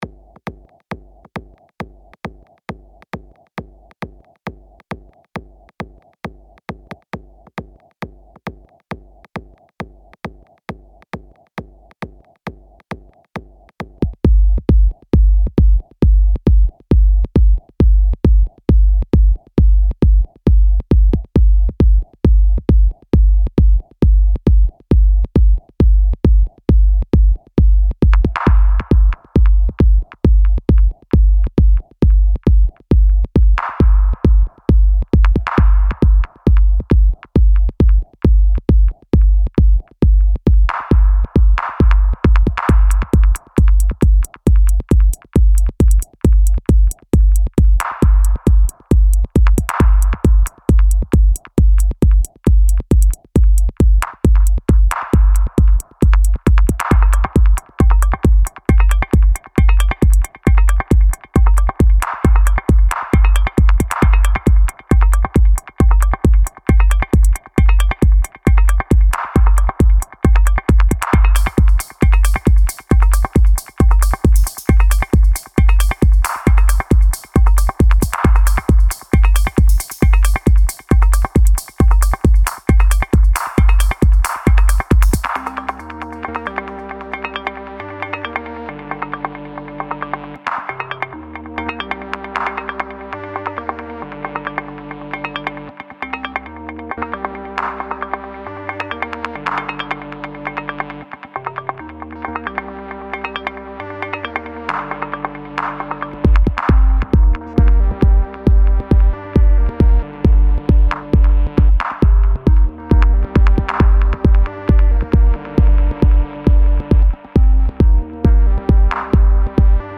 little something from Digitakt stock, nothing exciting